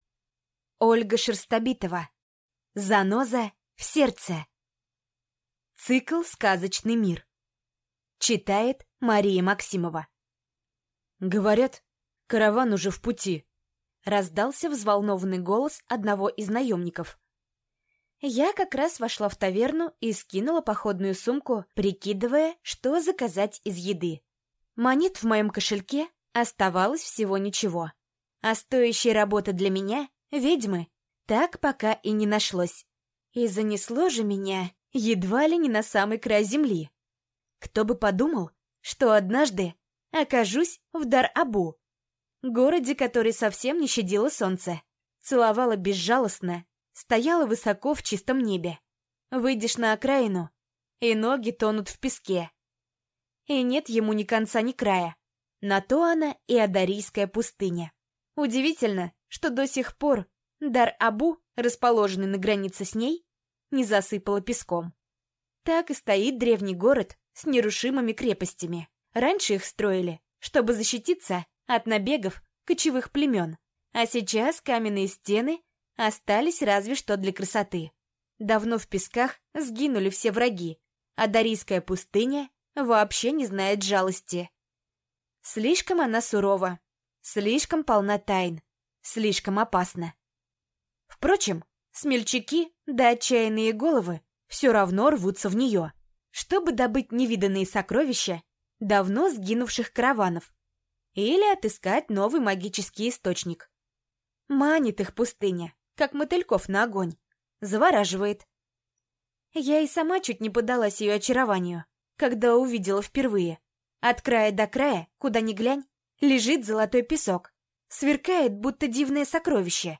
Аудиокнига Заноза в сердце | Библиотека аудиокниг